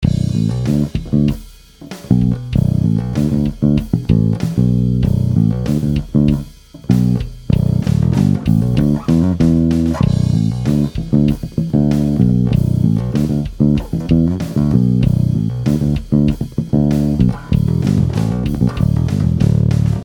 Headless Bass selber bauen
Die Bünde sind noch nicht abgerichtet, aber ich habe schon einmal ein kleines Soundfile eingespielt.
Ich spiele ja normalerweise keine 5-Saiter, aber so eine tiefe H-Saite hat schon was.
Klasse Sound, der knurrt schön.